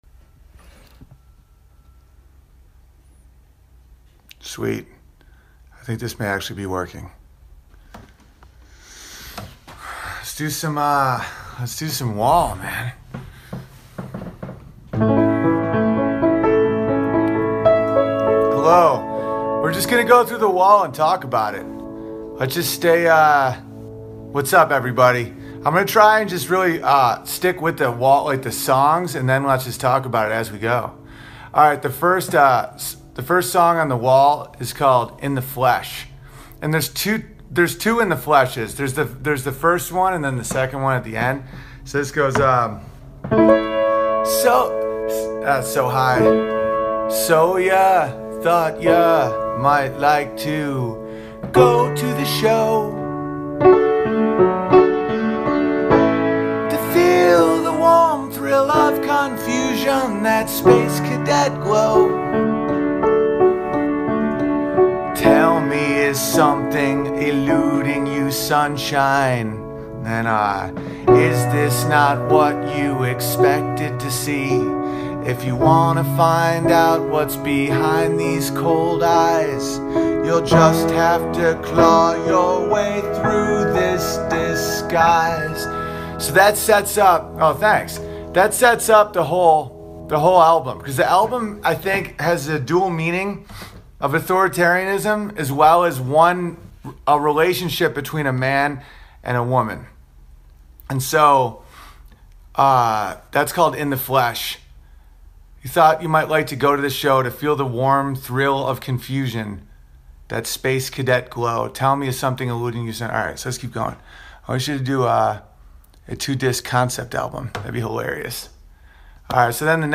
This ep is a little disorganized and I talk a lot with people on a live stream but it was the start o...